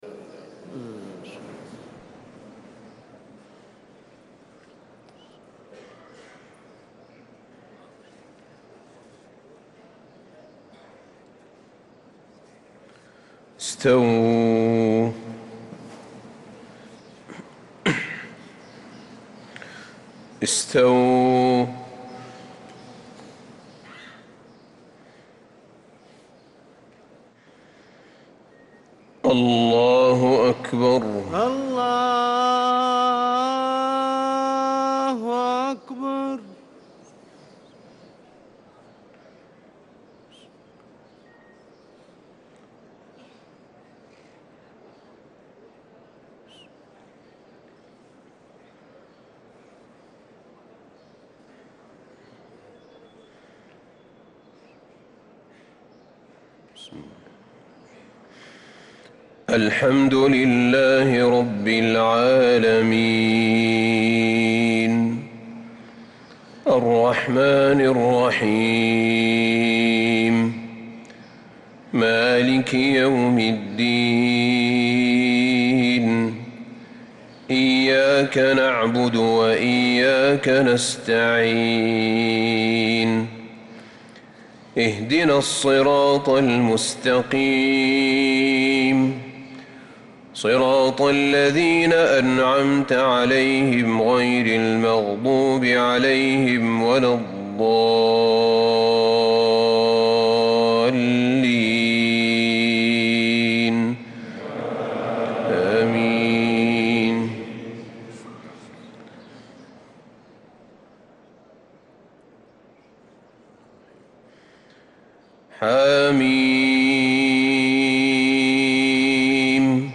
صلاة الفجر للقارئ أحمد بن طالب حميد 22 شوال 1445 هـ
تِلَاوَات الْحَرَمَيْن .